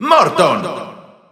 Announcer pronouncing Morton in Spanish.
Category:Bowser Jr. (SSBU) Category:Announcer calls (SSBU) You cannot overwrite this file.
Morton_Spanish_Announcer_SSBU.wav